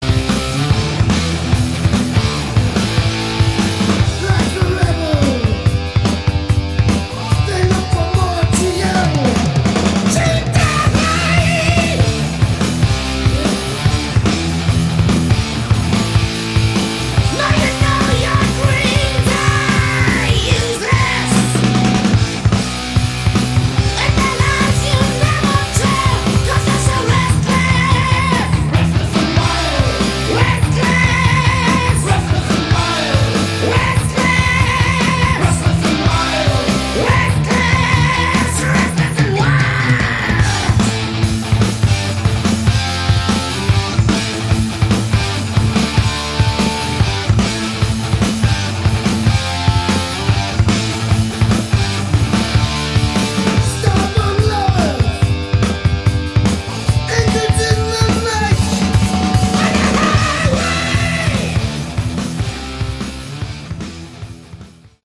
Category: Metal